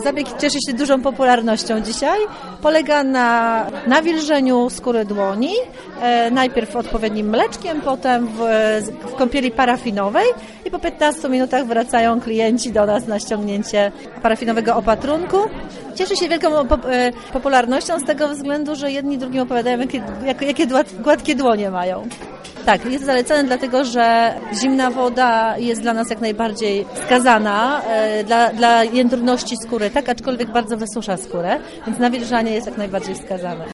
1-kosmetyczka.mp3